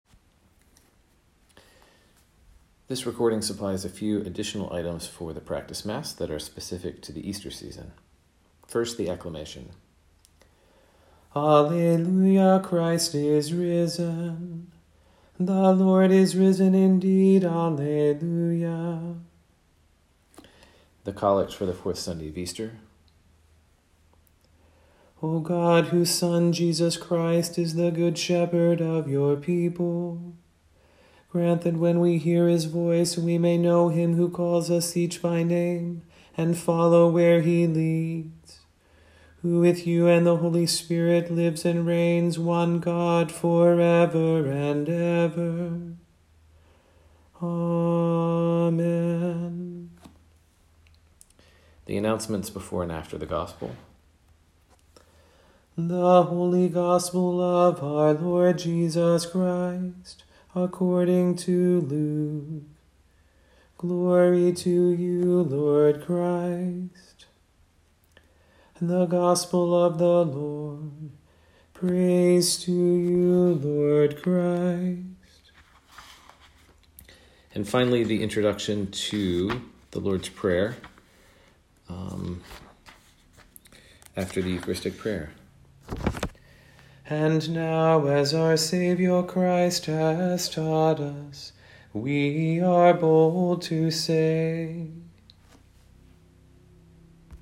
Easter chant for the practice mass